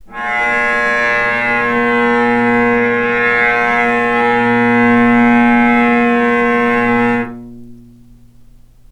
healing-soundscapes/Sound Banks/HSS_OP_Pack/Strings/cello/sul-ponticello/vc_sp-A#2-mf.AIF at 01ef1558cb71fd5ac0c09b723e26d76a8e1b755c
vc_sp-A#2-mf.AIF